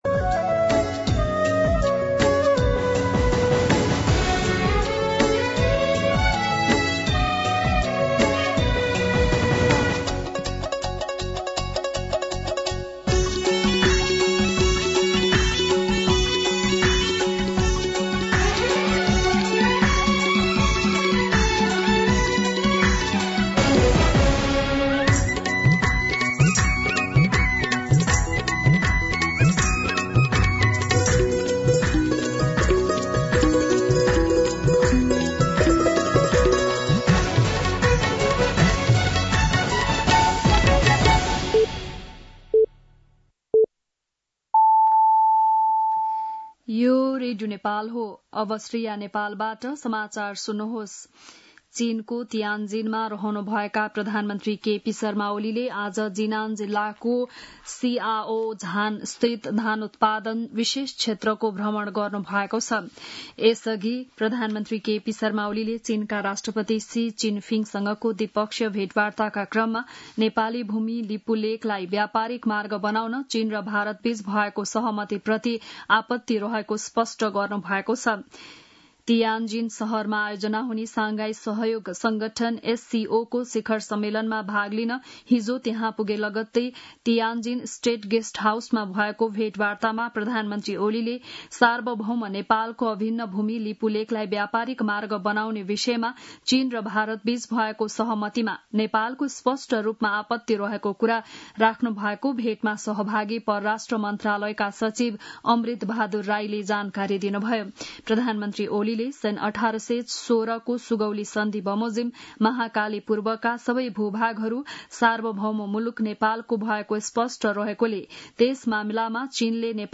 बिहान ११ बजेको नेपाली समाचार : १८ पुष , २०२६